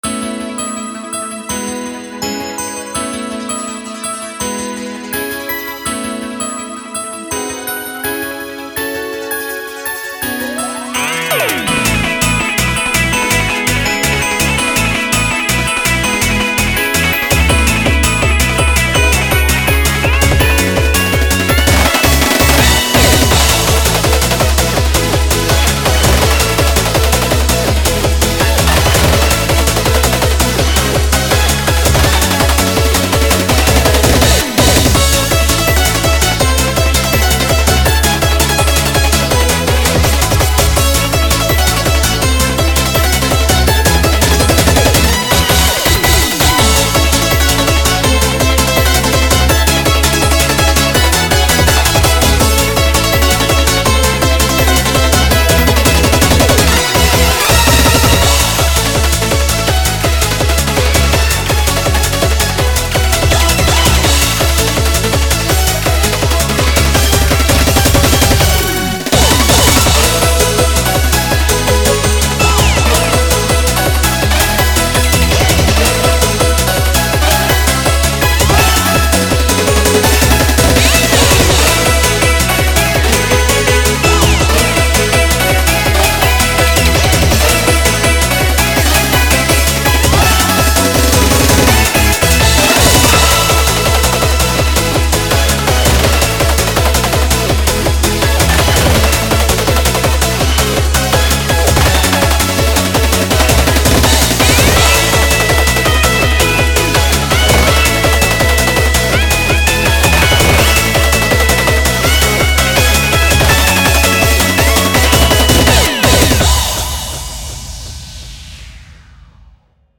Genre: [Eurobeat]
BPM: 165
So, here's a little happy eurobeat music for y'all.
Sounds like something from a video game.
It almost feels future bass in places.